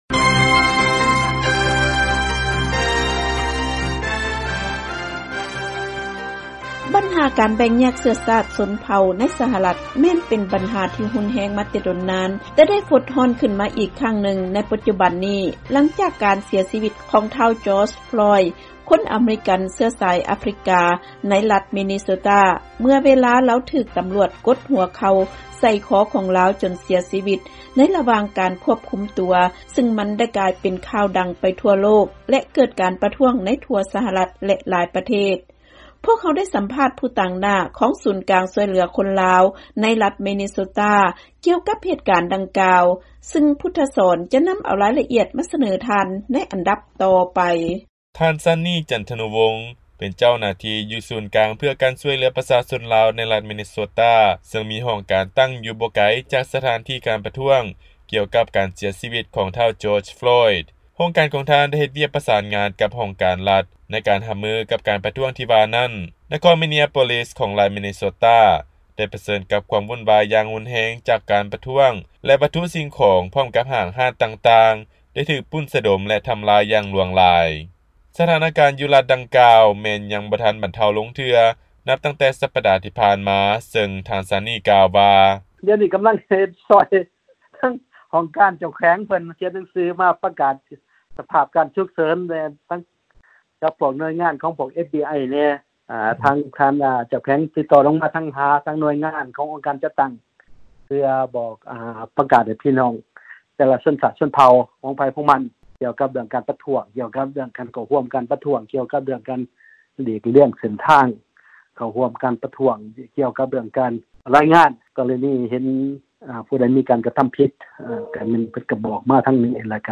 ຟັງລາຍງານ ສຳພາດພິເສດ